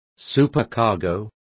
Complete with pronunciation of the translation of supercargo.